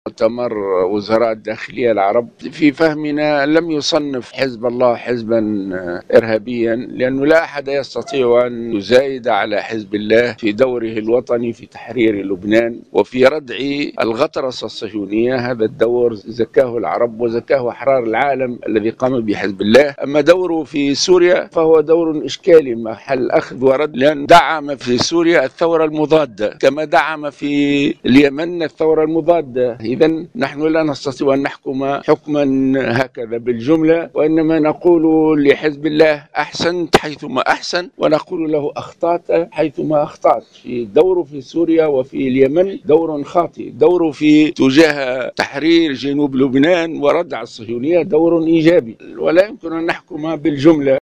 واستدرك الغنوشي بالقول إن حزب الله قام في المقابل بدور "اشكالي" في كل من سوريا واليمن، مضيفا أنه دعم الثورة المضادة بهذين البلدين، بحسب تعبيره في تصريحات صحفية على هامش الندوة الوطنية لحركة النهضة.